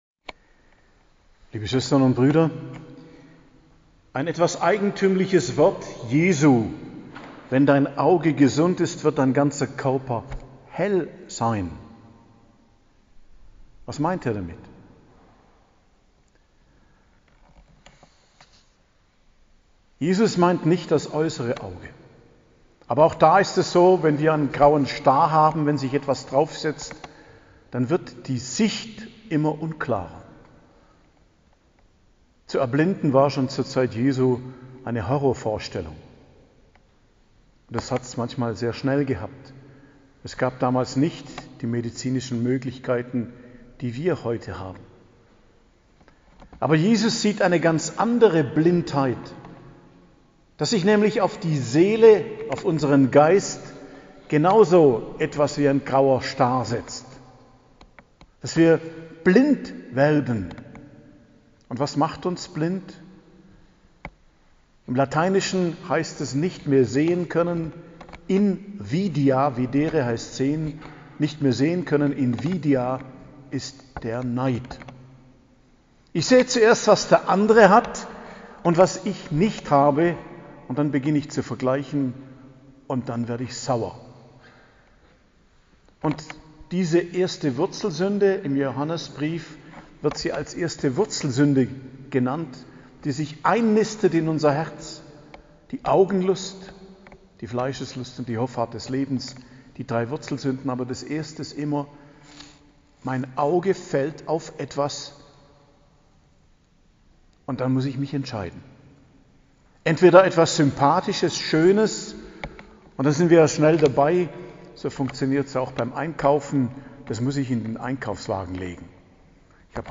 Predigt am Freitag der 11. Woche i.J., 17.06.2022 ~ Geistliches Zentrum Kloster Heiligkreuztal Podcast